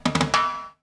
British SKA REGGAE FILL - 16.wav